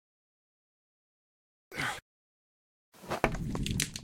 wood_fire1.ogg